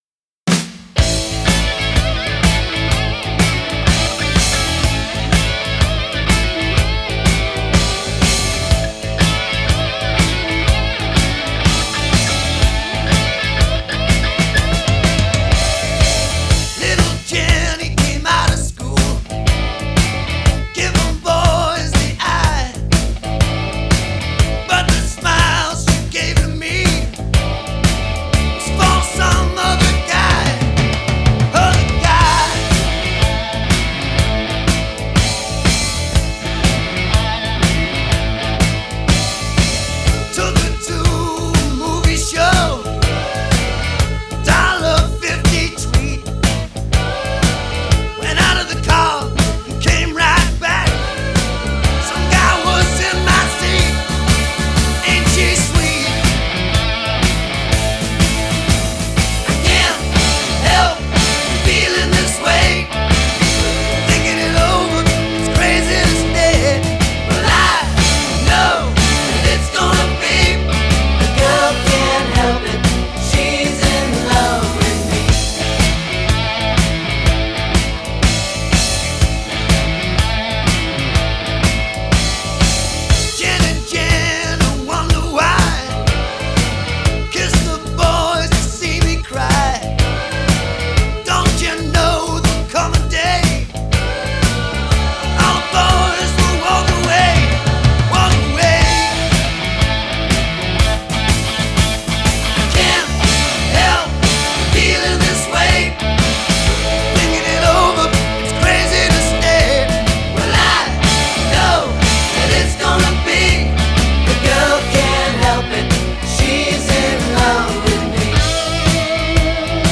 Genre: Soft Rock